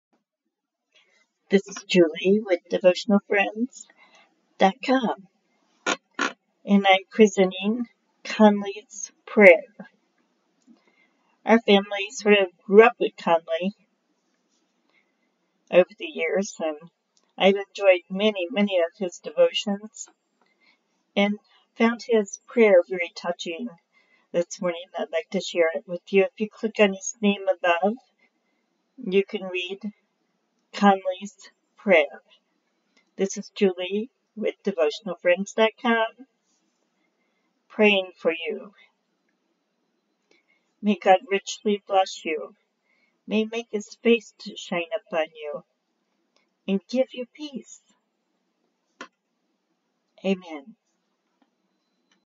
Prayer